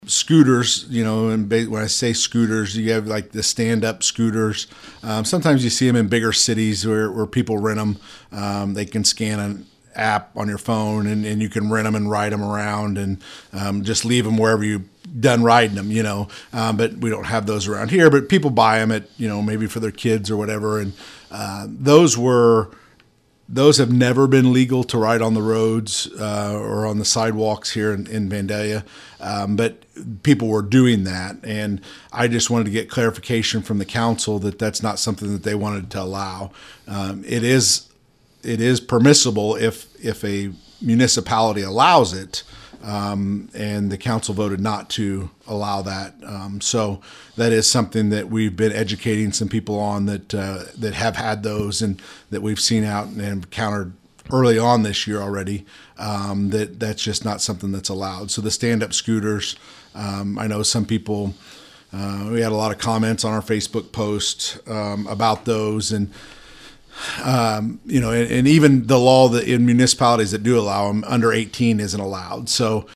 Vandalia Police Chief Jeff Ray was our guest this week for our podcast “Talking about Vandalia,” and discussed these issues.  But, there is one vehicle he can make clear that is not allowed on roadways and that is the standup scooter.